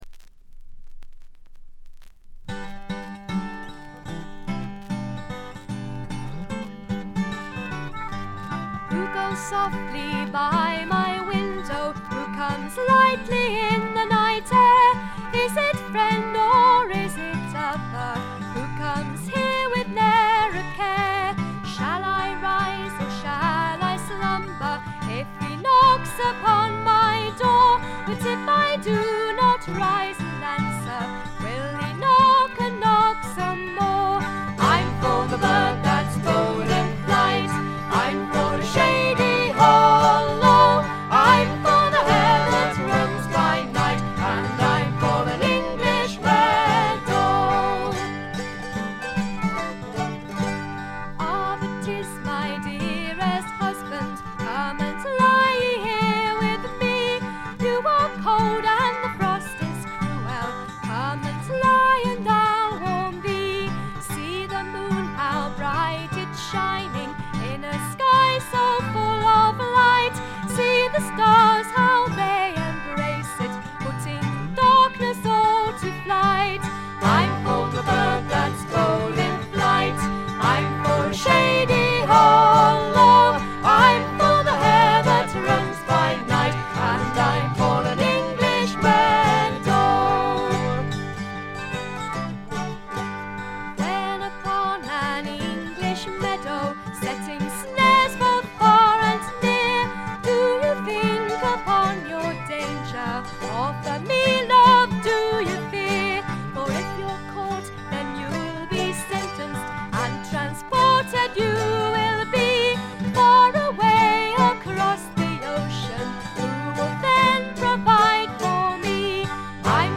静音部の軽微なバックグラウンドノイズ程度。
そこに哀愁の笛の音やコンサーティナのメロディが流れてくると、英国フォーク好きには至福の時間が約束されたようなものですね。
試聴曲は現品からの取り込み音源です。